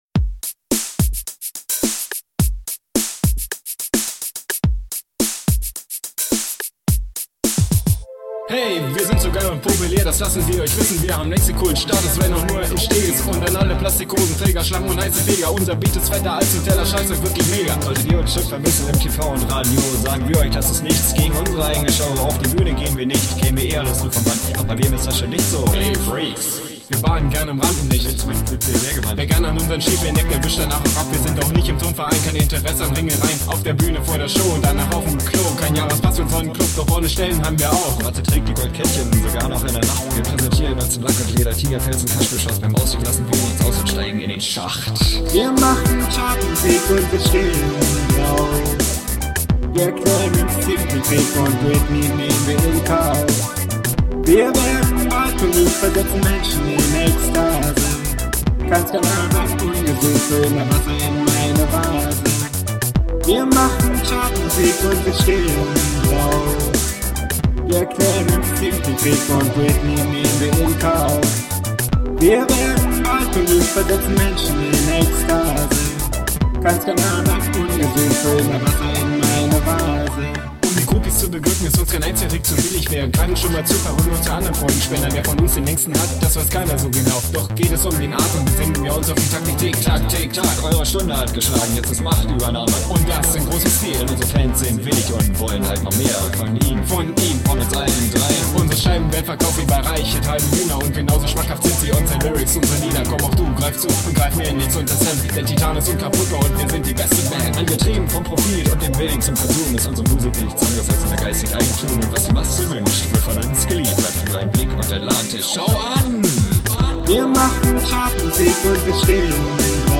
Wir machen Chart-Musik und wir stehen drauf!!!